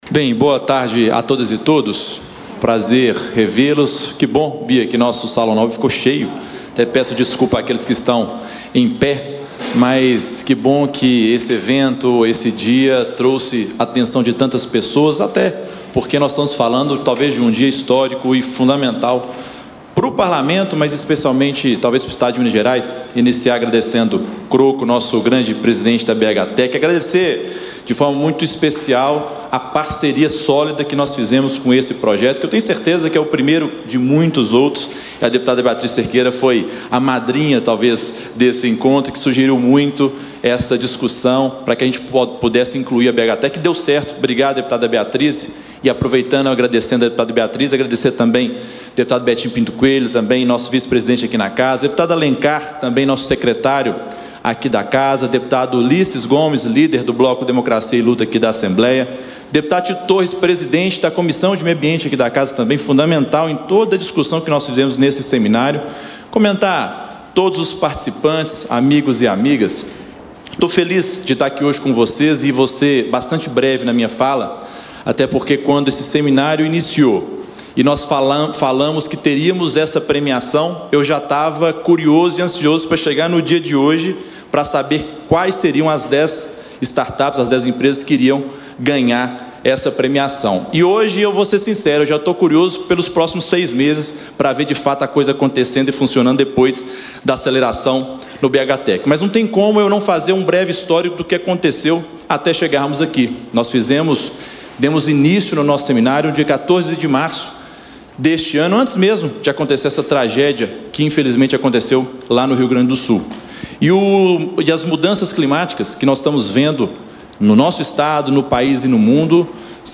Discursos e Palestras
Durante abertura da solenidade de entrega do Prêmio Assembleia de Incentivo a Inovação - Crise Climática, o Presidente da Assembleia Legislativa de Minas lembrou que as ações para auxiliar Minas Gerais a enfrentar os efeitos das variações do clima continuam na pauta dos deputados.